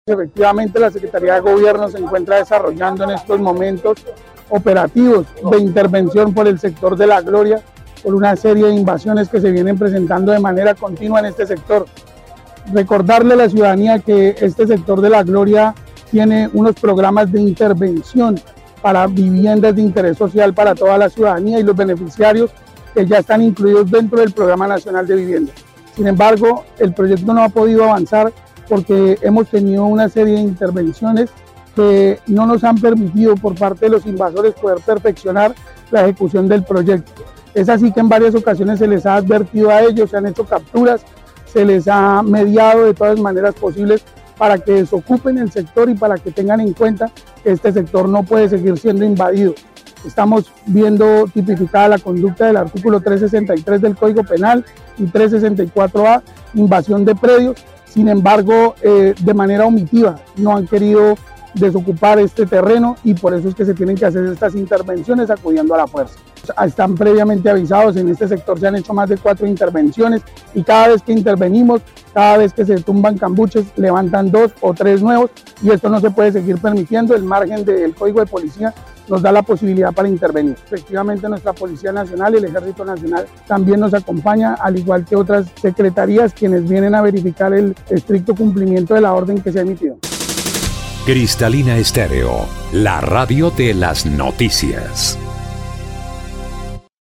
El secretario de Gobierno, Jaime Eduardo Becerra Correa, explicó que en estos terrenos se construirán con el apoyo del gobierno nacional, viviendas de interés social.